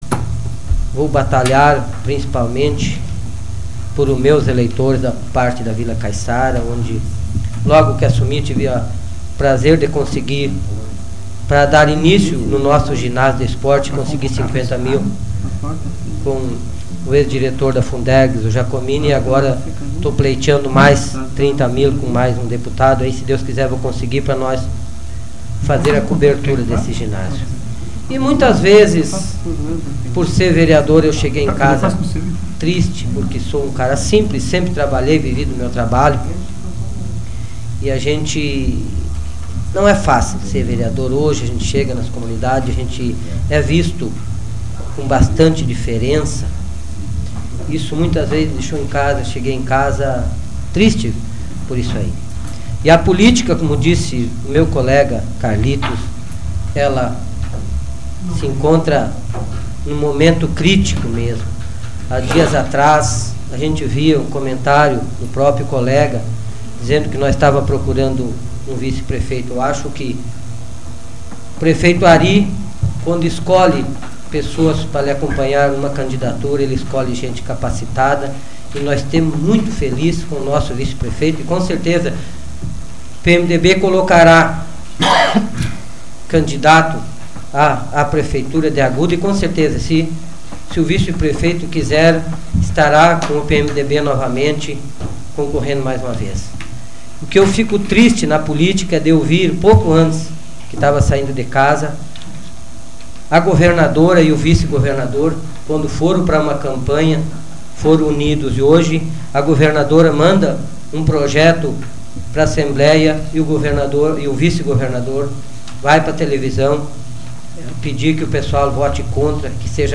Áudio da 107ª Sessão Plenária Ordinária da 12ª Legislatura, de 19 de novembro de 2007